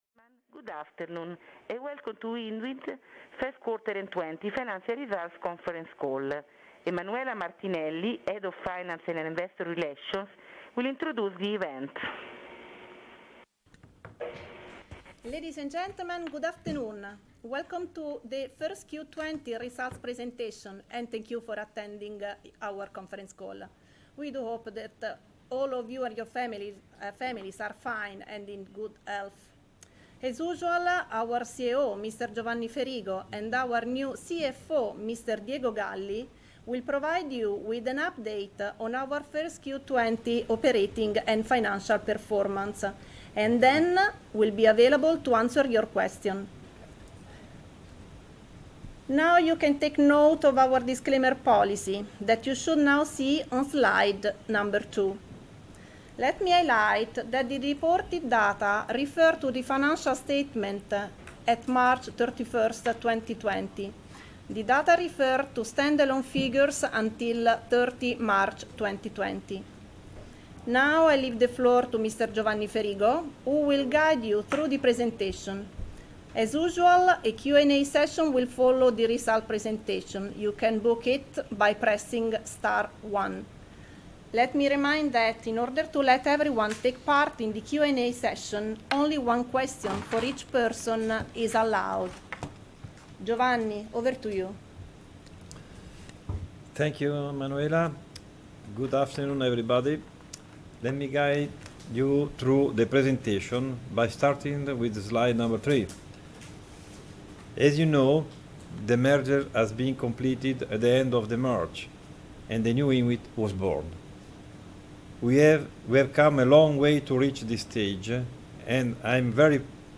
Follow and download the conference call Download Investor Presentation Download Financial Data (EXCEL) Download the…